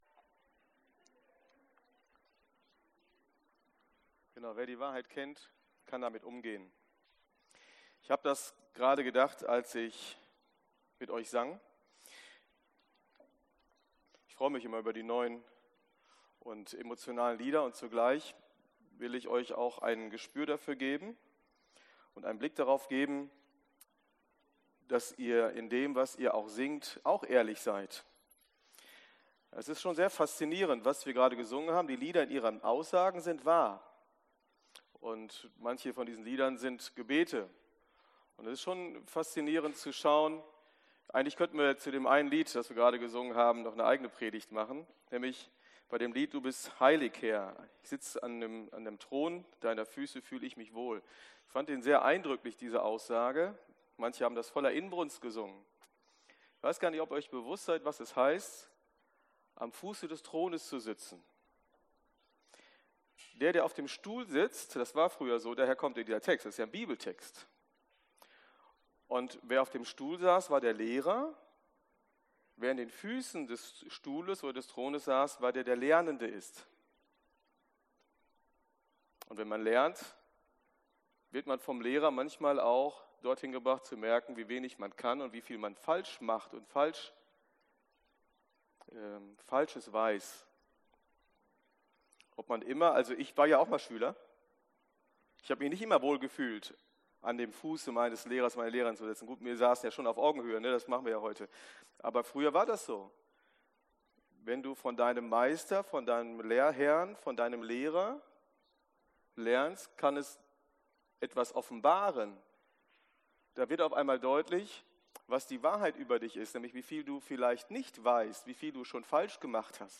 PREDIGTEN - Ev.